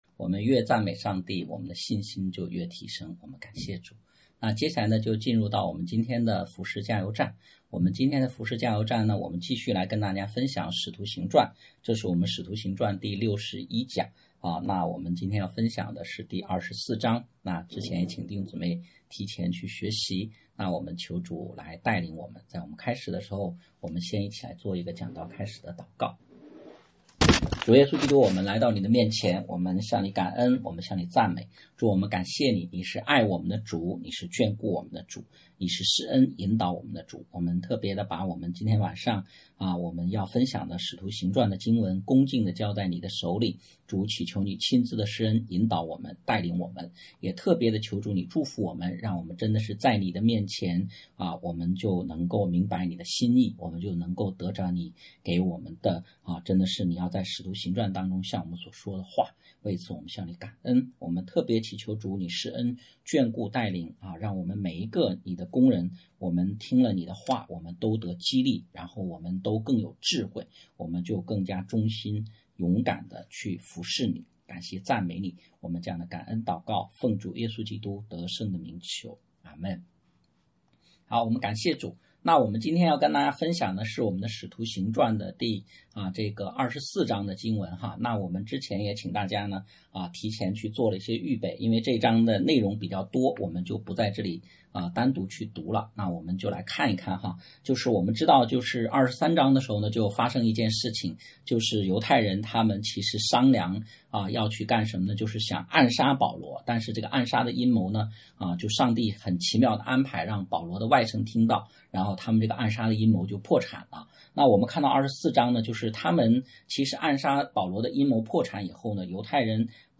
《使徒行传》系列讲道
讲道录音 点击音频媒体前面的小三角“►”就可以播放 https